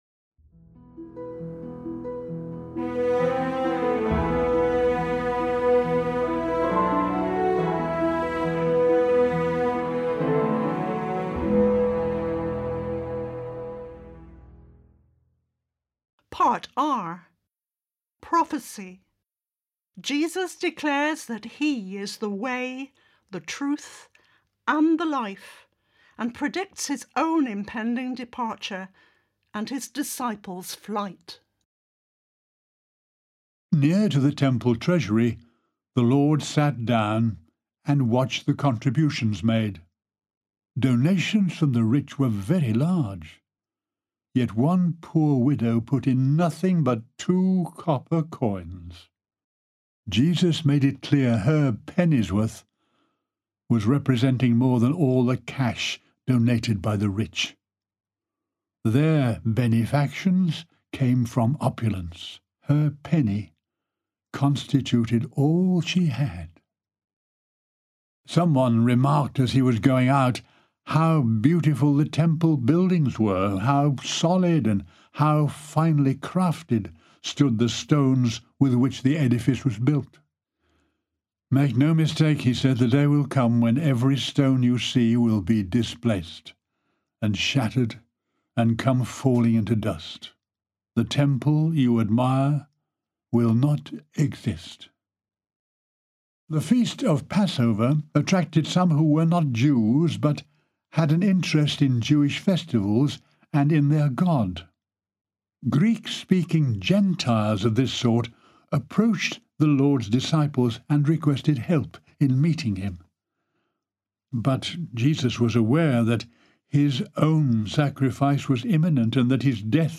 This gospel version, written in verse, is the outcome of a deep engagement with God in Christ. To listen to the sound files of this gospel being read out loud is to be taken onto a